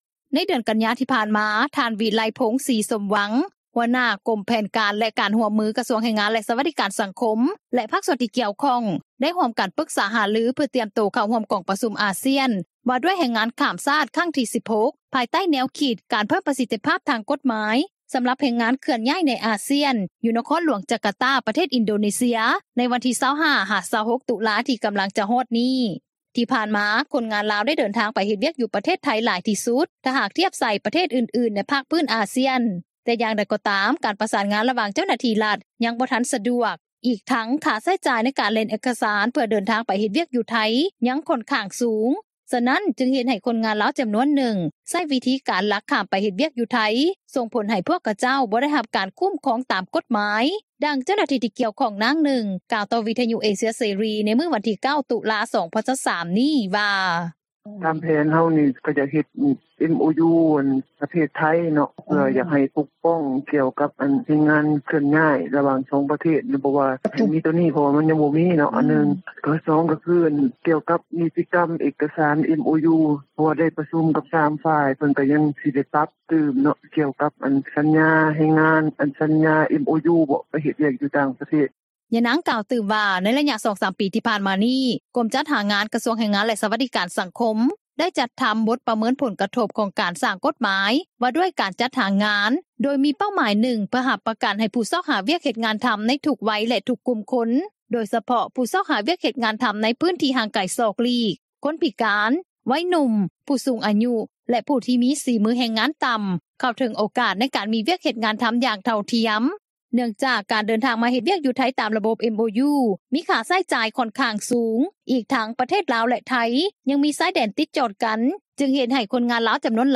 ດັ່ງເຈົ້າໜ້າທີ່ທີ່ກ່ຽວຂ້ອງ ນາງນຶ່ງ ກ່າວຕໍ່ວິທຍຸເອເຊັຽເສຣີ ໃນມື້ວັນທີ 9 ຕຸລາ 2023 ນີ້ວ່າ:
ດັ່ງເຈົ້າໜ້າທີ່ ທີ່ກ່ຽວຂ້ອງ ທ່ານນຶ່ງ ກ່າວຕໍ່ວິທຍຸເອເຊັຽເສຣີ ໃນມື້ດຽວກັນນີ້ວ່າ:
ດັ່ງຄົນງານລາວ ທີ່ເຮັດວຽກນໍາບໍຣິສັດຈີນ ຢູ່ໄທຍ ນາງນຶ່ງ ກ່າວວ່າ: